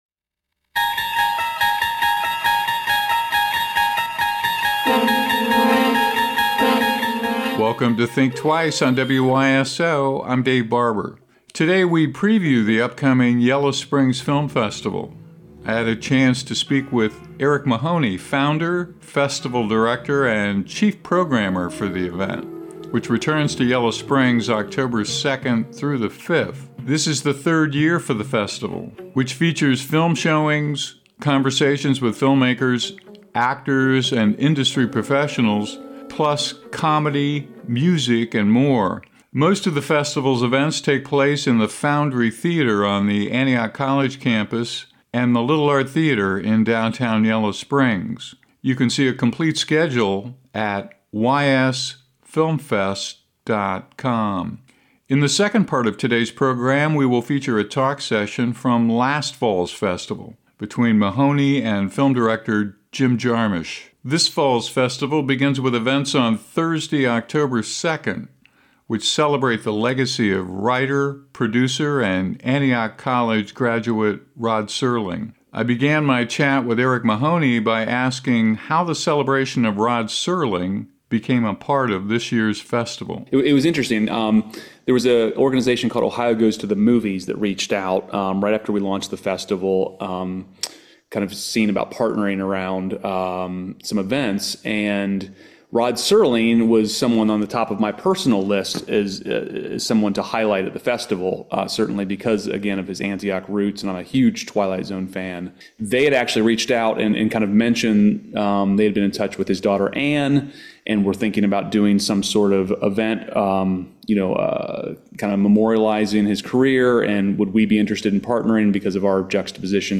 Plus a rare interview with legendary filmmaker Jim Jarmusch on collaboration.
think-twice-yellow-springs-film-festival-2025-jarmusch-interview-wyso.mp3